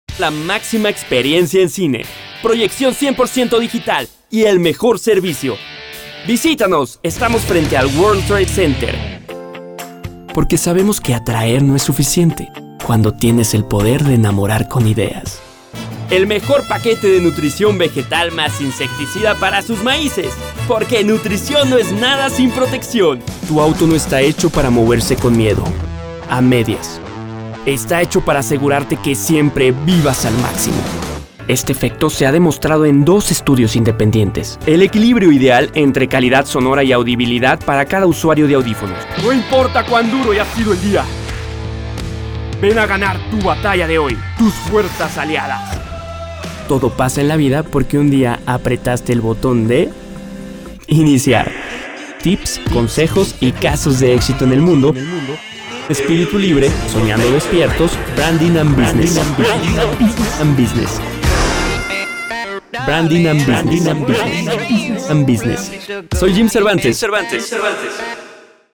Spots publicitarios
Soy un locutor y especialista en Audiomarketing.